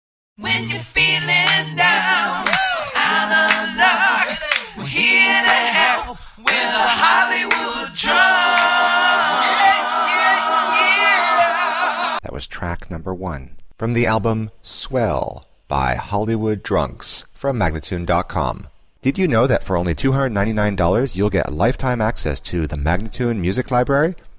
A swirling vortex of rock, funk, rap,and alternative.
Vox
Guitars, Bass
Keys
Drums and Percussion
Tagged as: Alt Rock, Rock, Pop